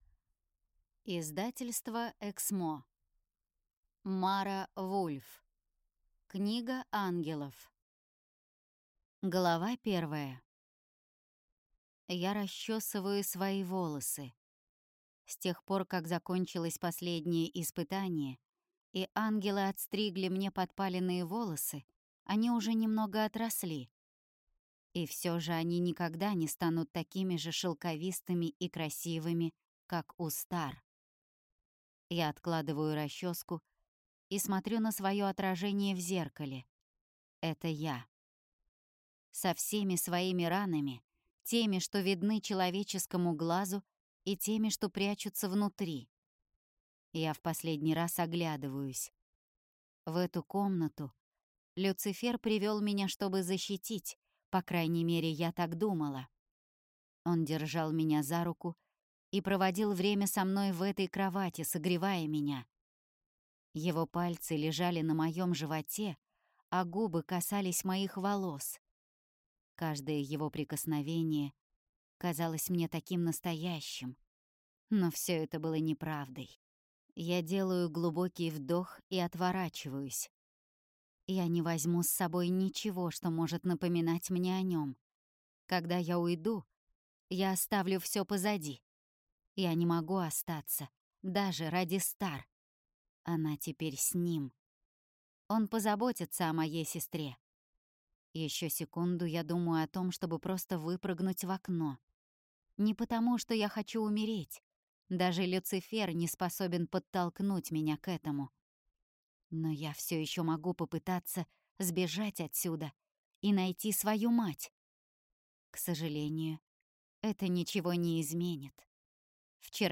Аудиокнига Книга ангелов | Библиотека аудиокниг